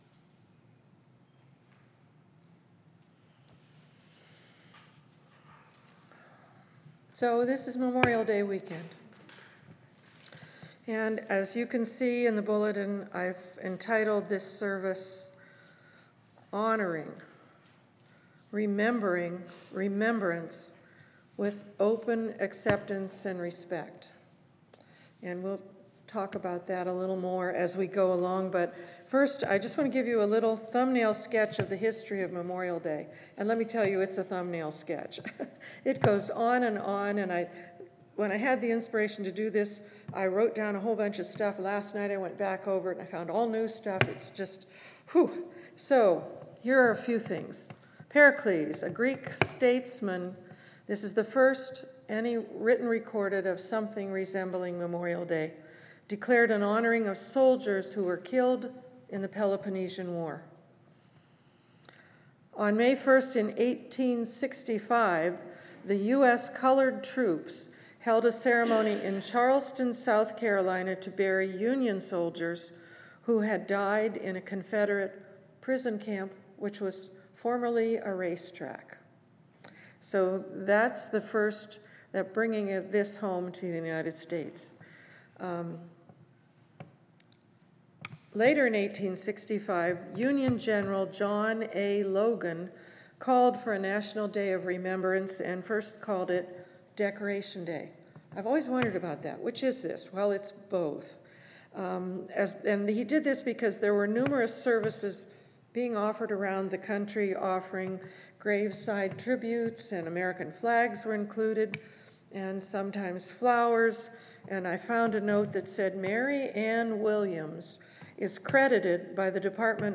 This will be an optional participatory service.